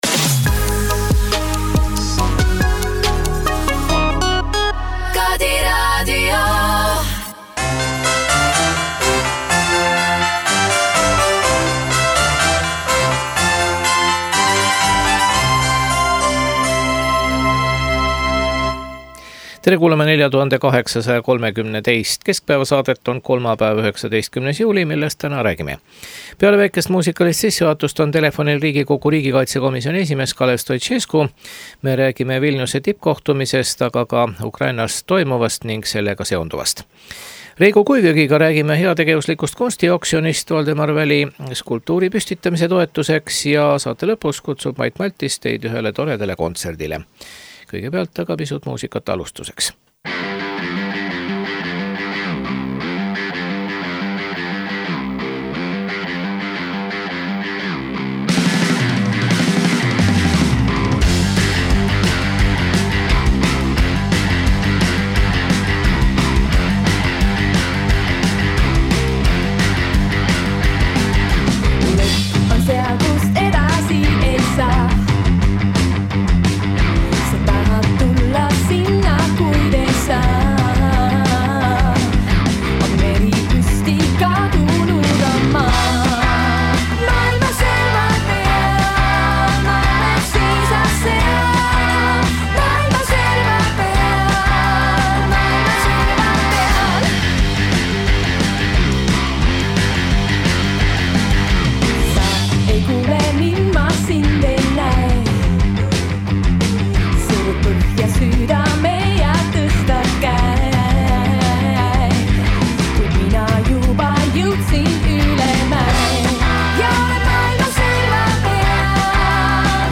Telefonil on Riigikogu riigikaitse komisjoni esimees Kalev Stoicescu.